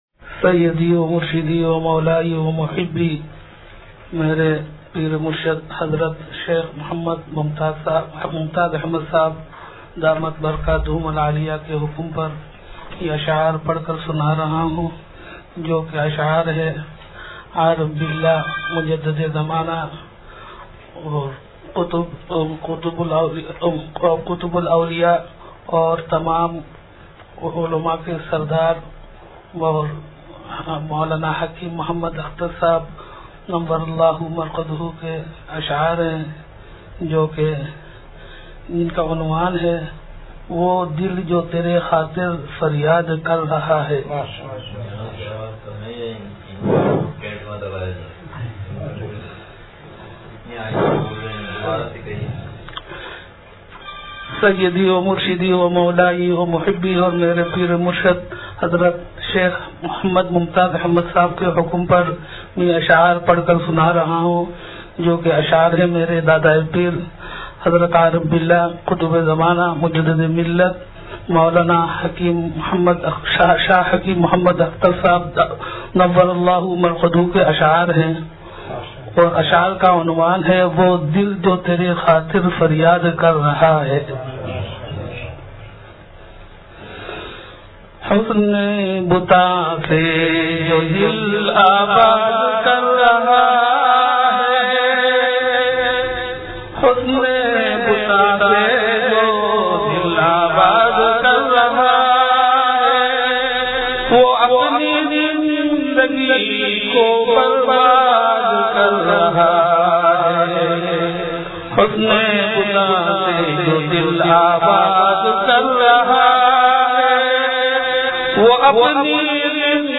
وہ دل جو تیری خاطر فریاد کر رہا ہے – بچیوں کے حفظ و ختم قرآن کریم کے موقع پر بیان